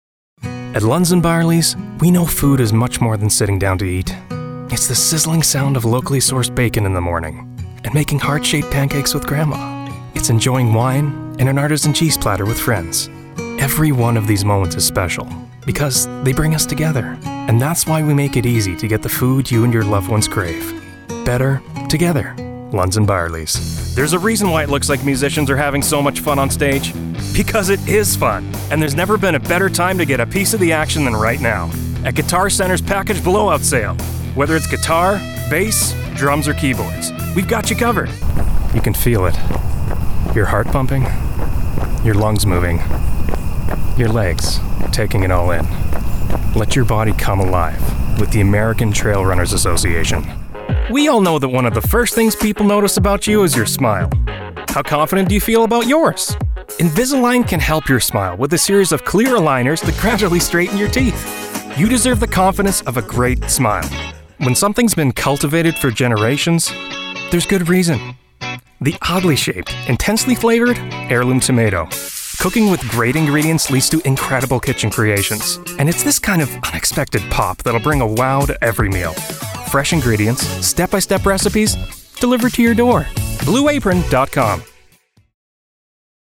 sehr variabel, dunkel, sonor, souverän, markant
Mittel minus (25-45)
Commercial
Commercial (Werbung)